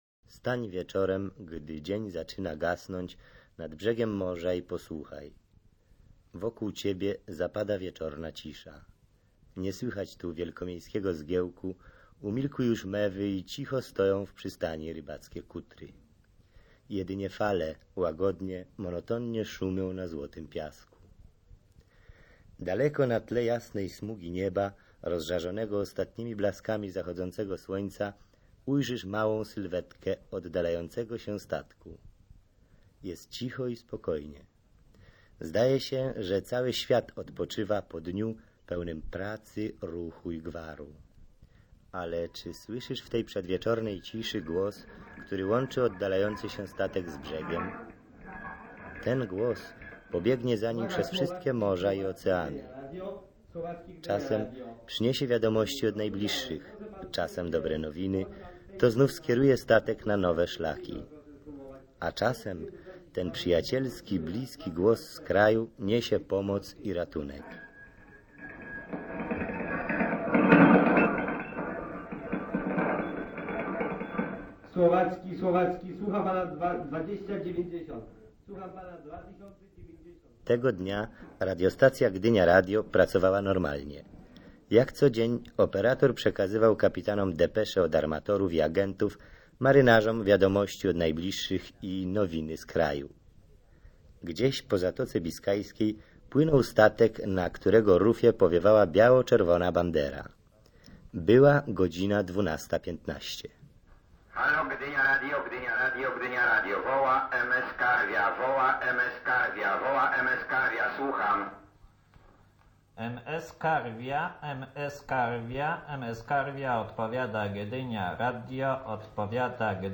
Audycja dotyczyła właśnie bardzo konkretnej pracy stacji brzegowej Gdynia Radio.